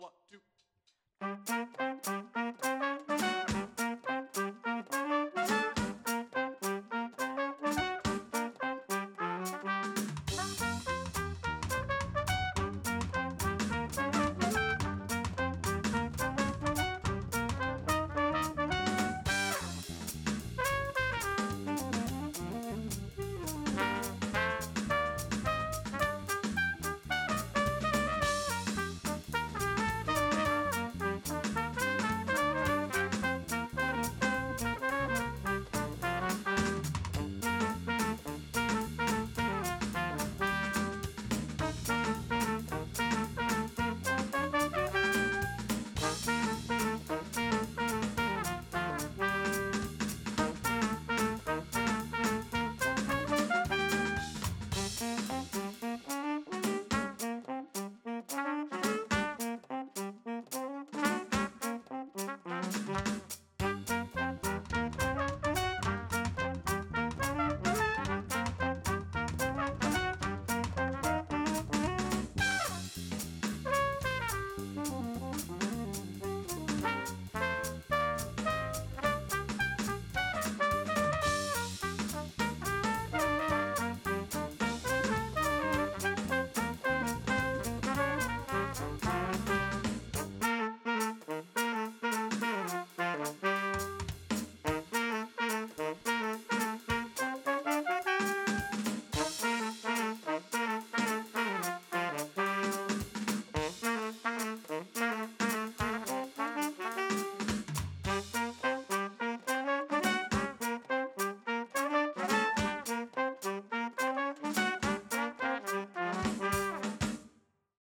JAZZ SESH
In an effort to squeeze as many instruments as possible into a tiny room, the jazz band recorded this!